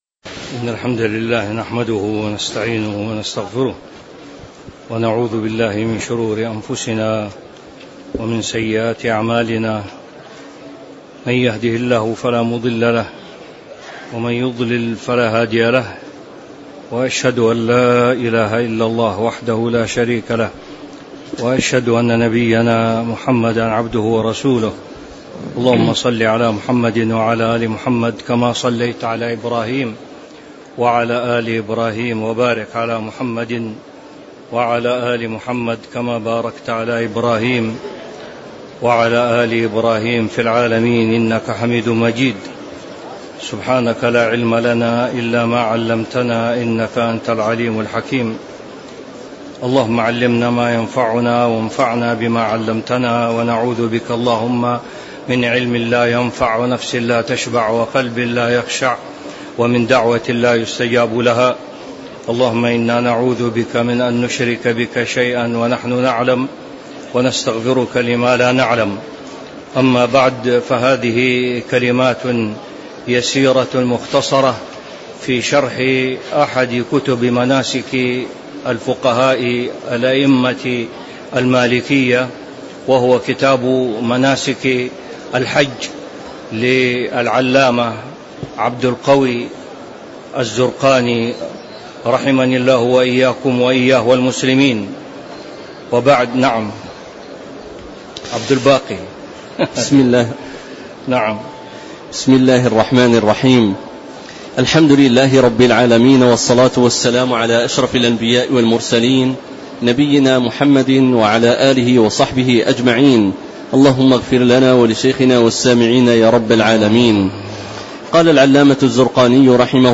تاريخ النشر ٢٩ ذو القعدة ١٤٤٦ هـ المكان: المسجد النبوي الشيخ